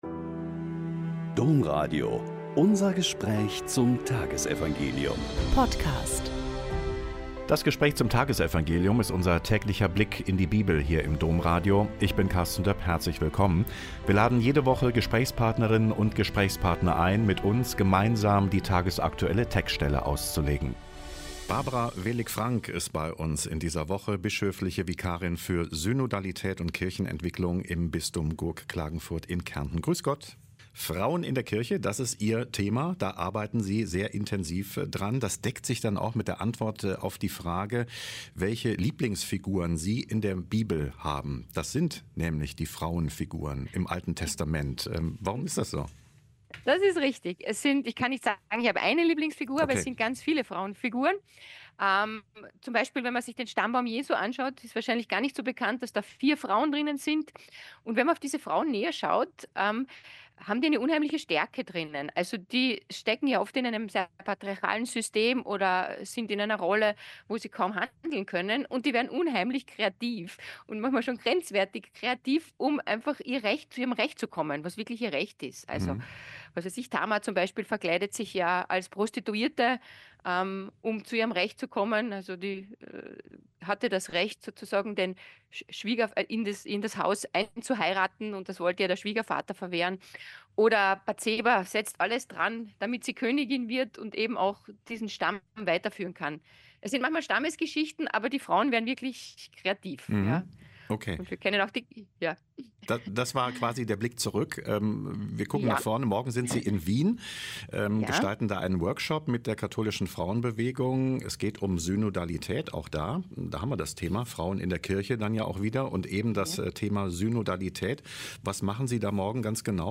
Lk 19,41-44 - Gespräch